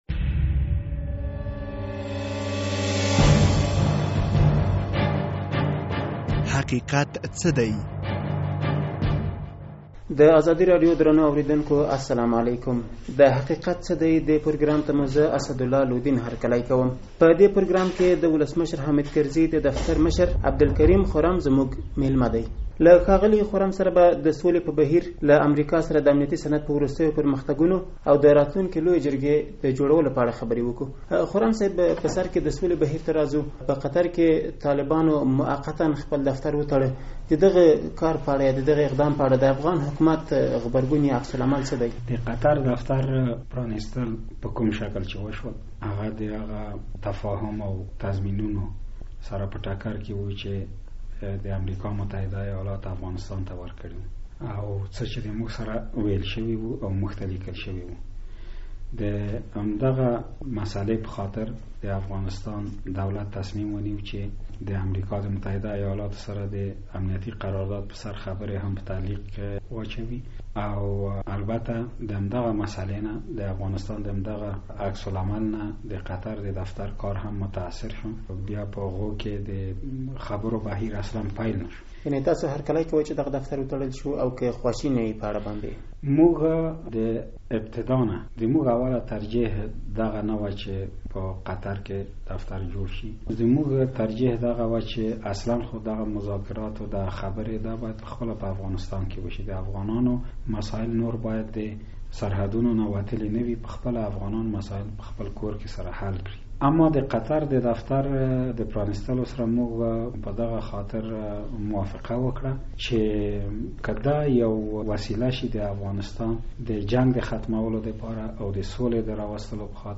د حقیقت څه دی په دې پروګرام کې د جمهوري ریاست د دفتر له مشر عبدالکریم خرم سره د سولې په بهیر، له امریکا سره د امنیتي سند په مسایلو او د راتلونکې لویې جرګې په اړه غږیدلي یو.